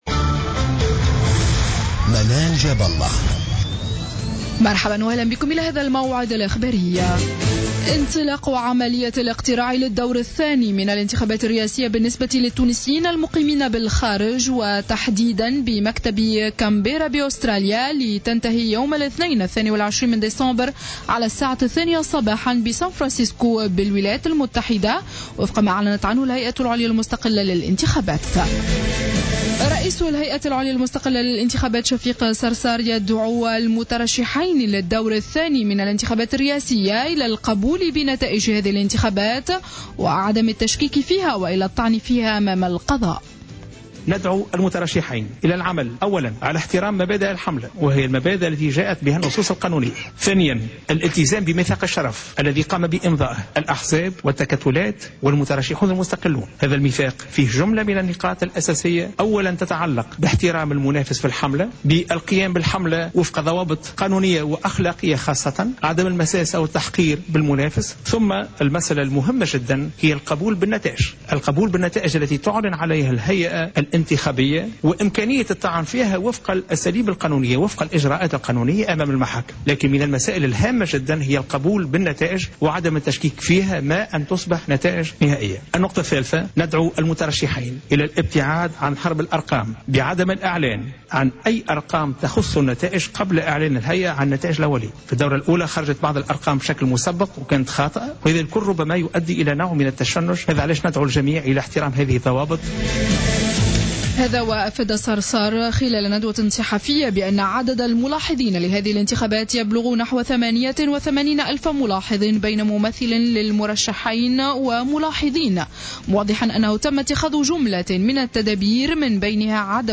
Journal Info 00h00 du 19-12-14